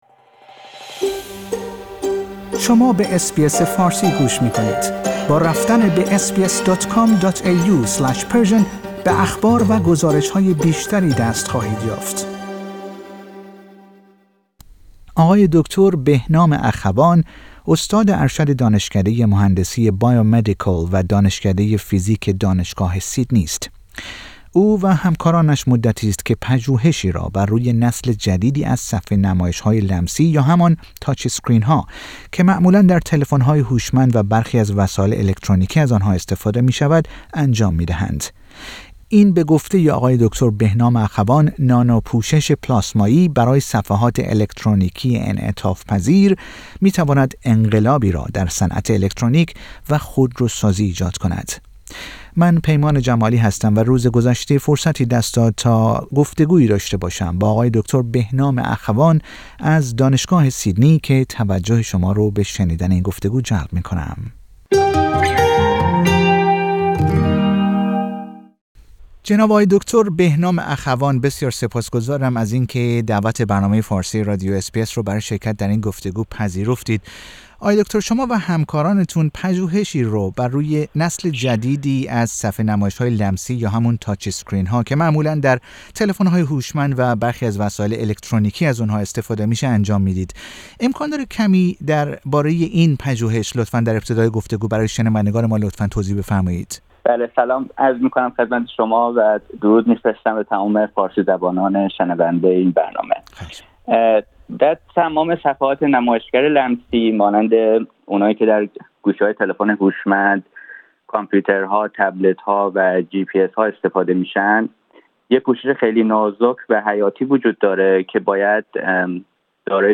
در گفتگو با رادیو اس بی اس فارسی